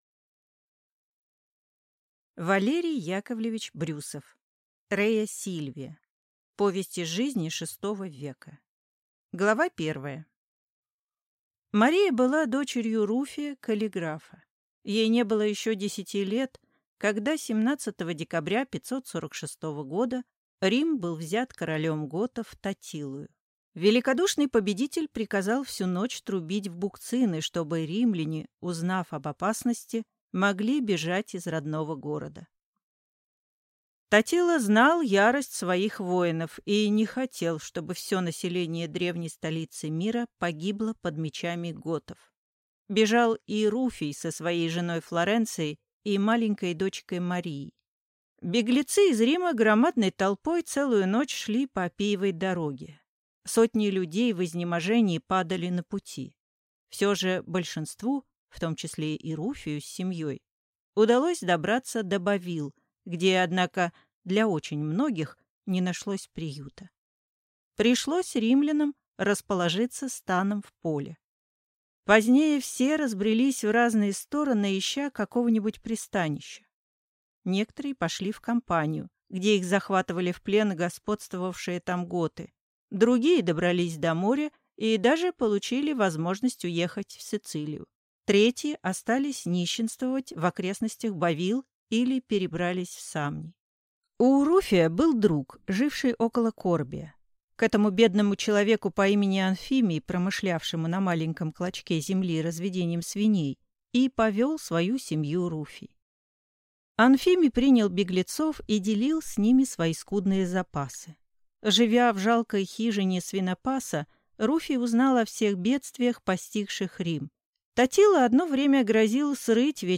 Аудиокнига Рея Сильвия | Библиотека аудиокниг
Aудиокнига Рея Сильвия Автор Валерий Брюсов